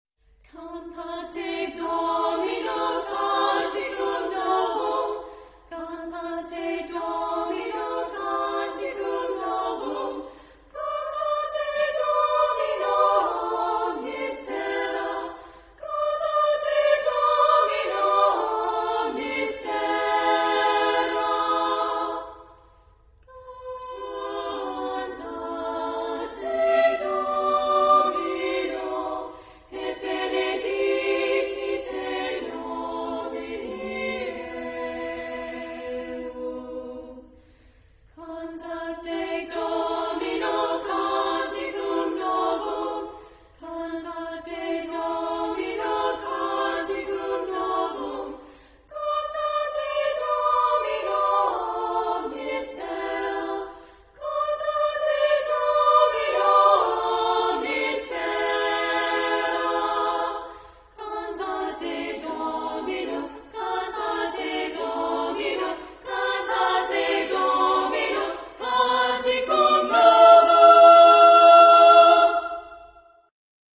Genre-Style-Form: Sacred ; Choir ; Motet
Mood of the piece: joyous
Type of Choir: SSA  (3 women voices )
Tonality: E major